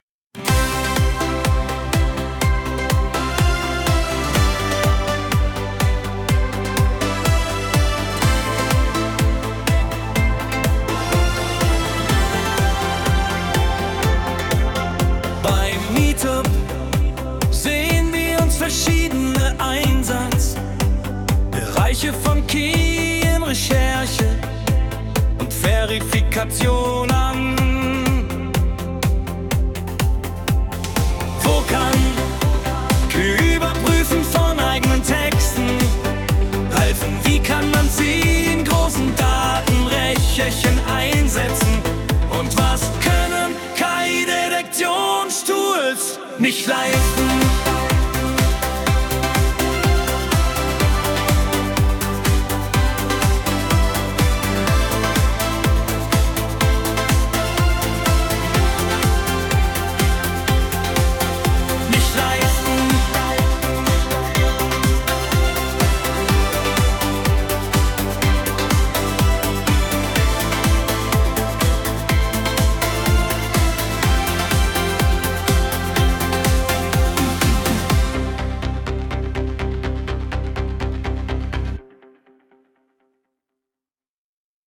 Der KI-generierte Song aus dem Teaser der Veranstaltung durfte natürlich nicht fehlen (Vorgabe: „stumpfer, aber unwiderstehlicher Schlager“):